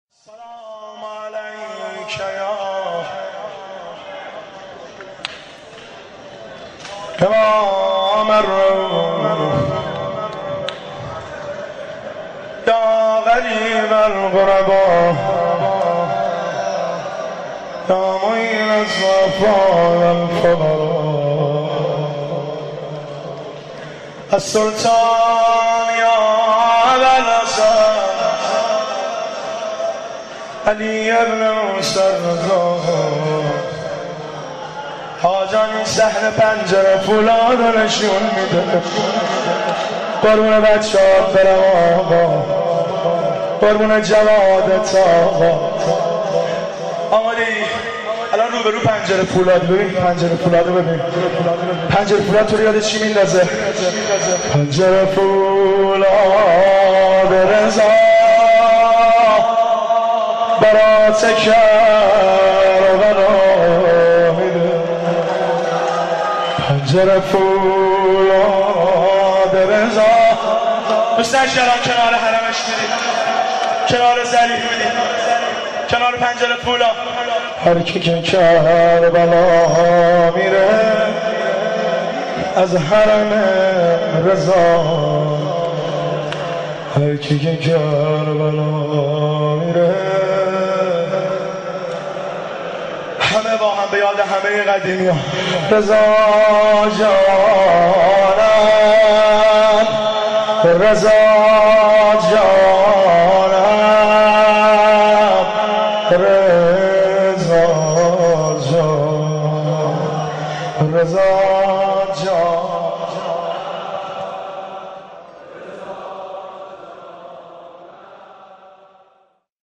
مناسبت : شهادت امام رضا علیه‌السلام
قالب : روضه